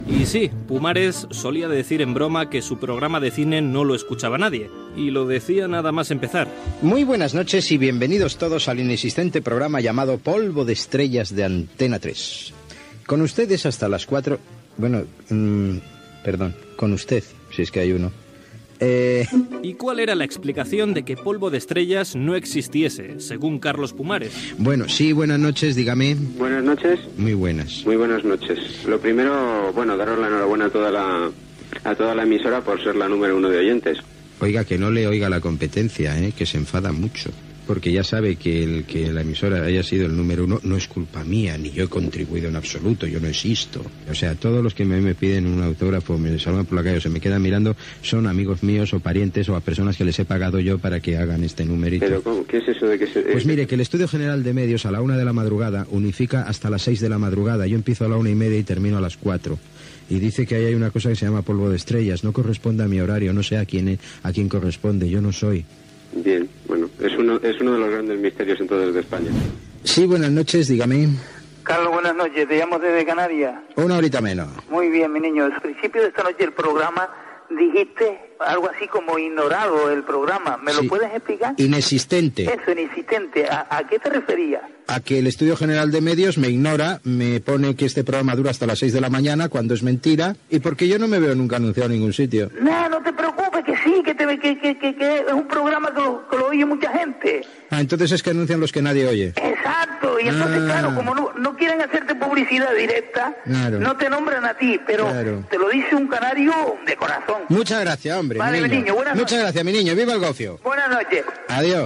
Divulgació
FM
Programa presentat per Carlos Alsina.